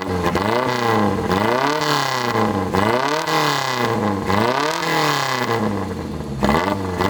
SJ20の猛烈サウンドはここで聴けます。
エンジン型式 ： F8A型水冷4サイクル直列4気筒